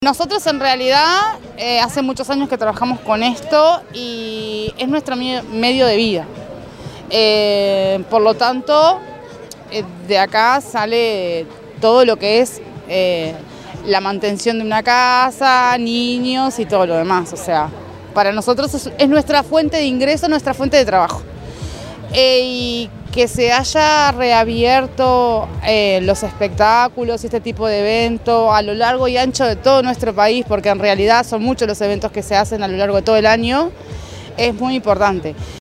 Fiesta de la Cerveza Artesanal en Parque del Plata
vendedora_de_garrapinada.mp3